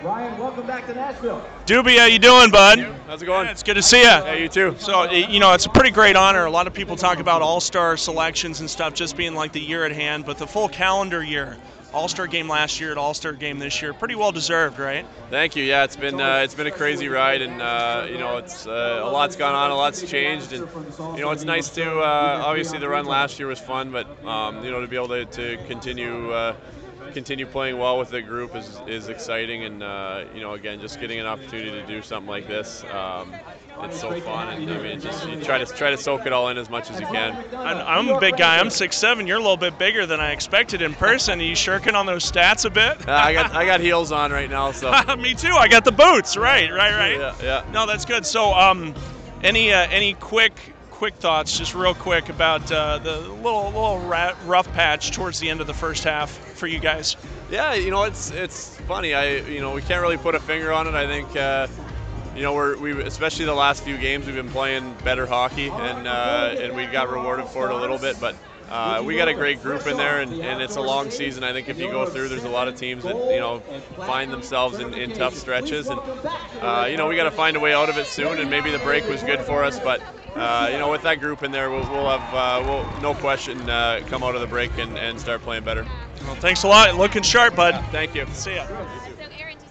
at the 2016 NHL All-Star Game.